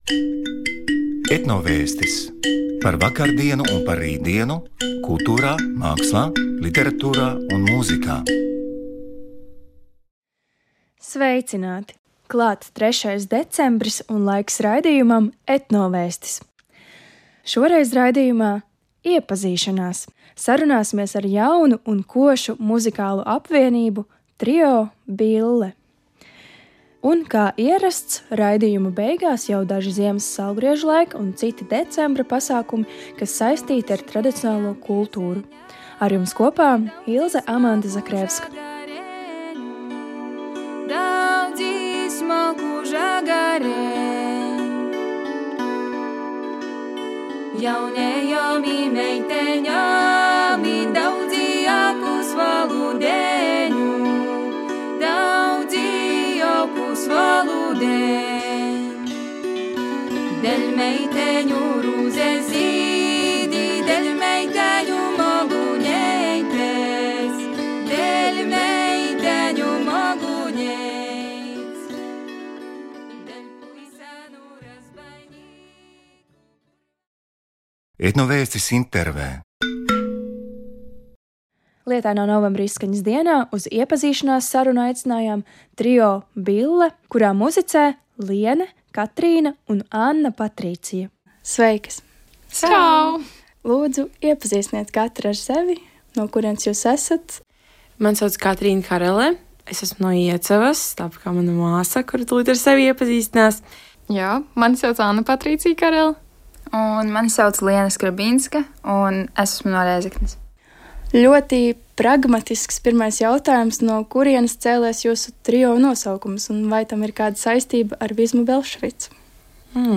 Sarunājamies ar jaunu un košu muzikālu apvienību – trio "Bille".